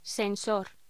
Locución: Sensor
voz